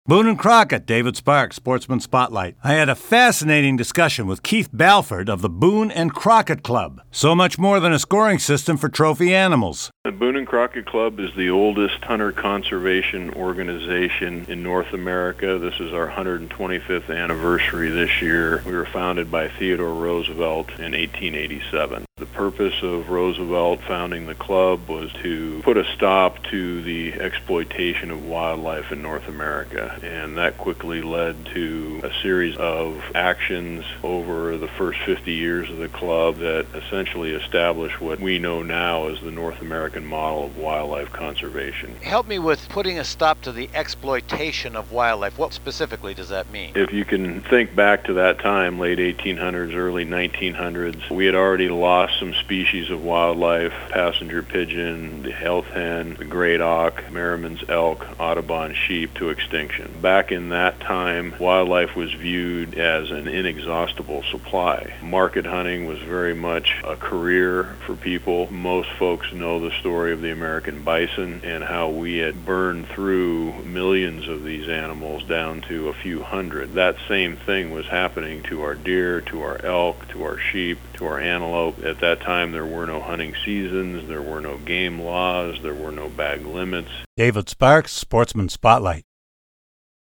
Fascinating discussion